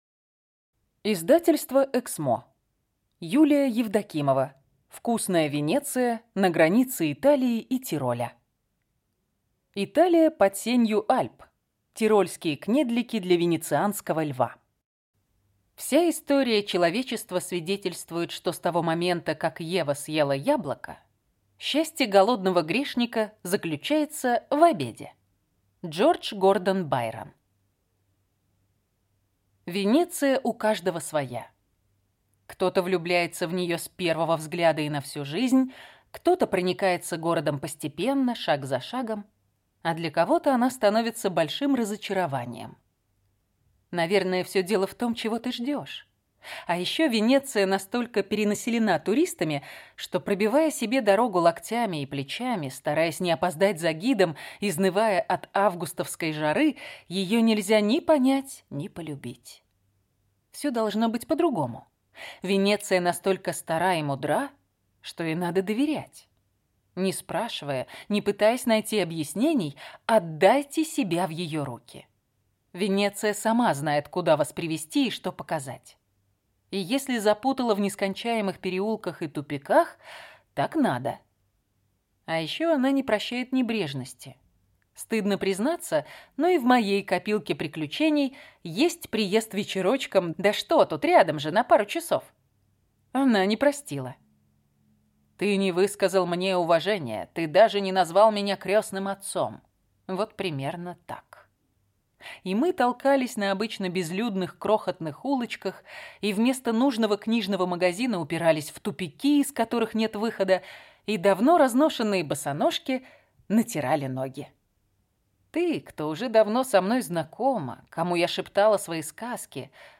Аудиокнига Вкусная Венеция. Любовь, еда и тайны северной Италии | Библиотека аудиокниг